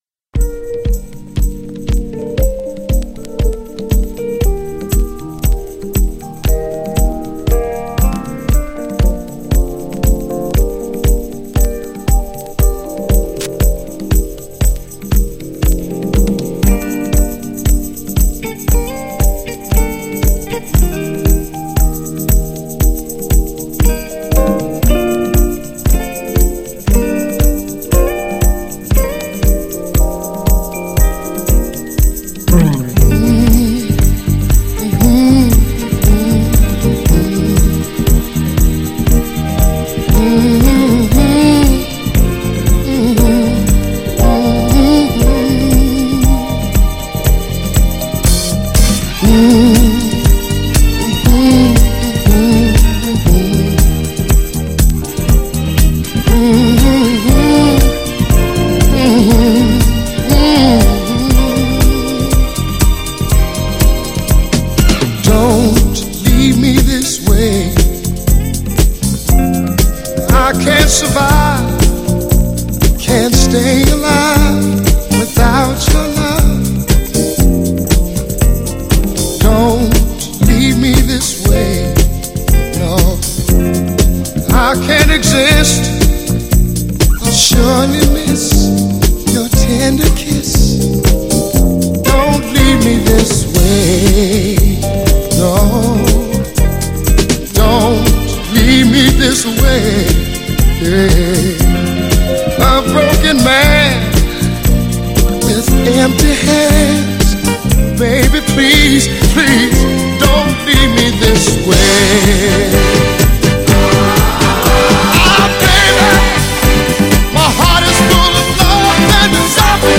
GENRE Dance Classic
BPM 111〜115BPM